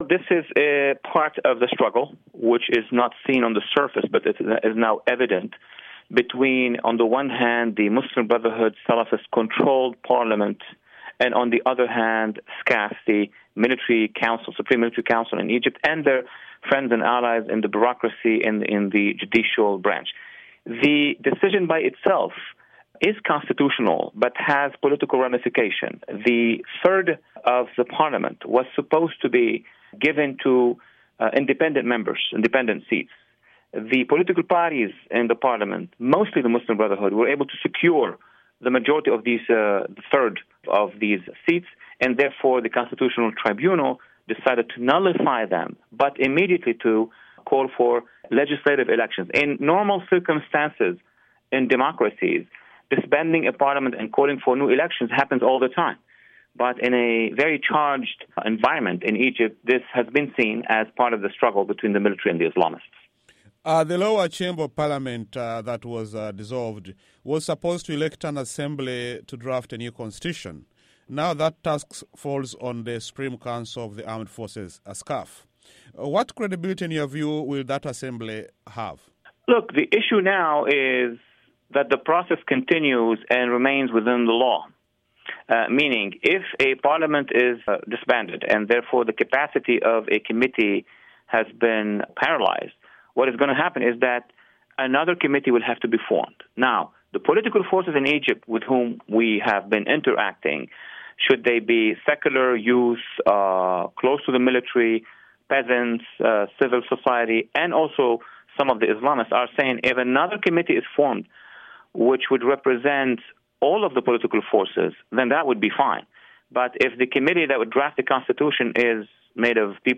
interview with Dr. Walid Phares